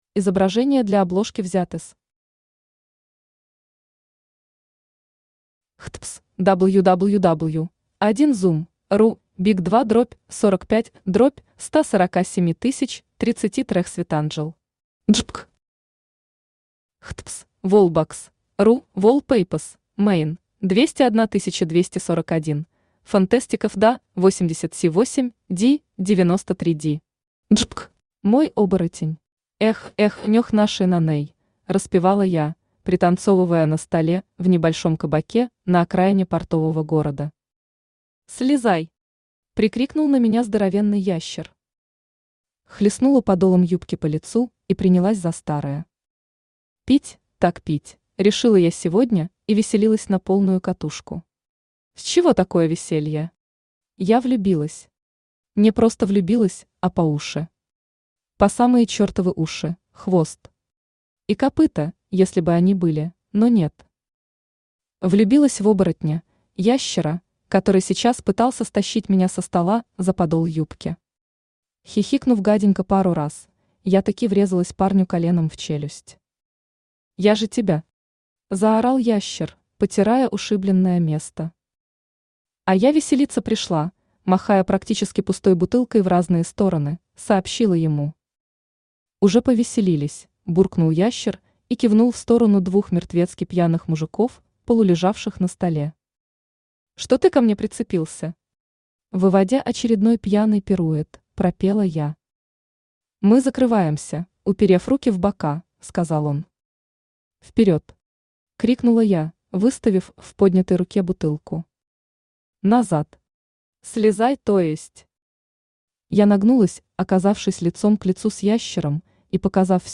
Аудиокнига Мой оборотень | Библиотека аудиокниг
Aудиокнига Мой оборотень Автор Дарья Сергеевна Урусова Читает аудиокнигу Авточтец ЛитРес.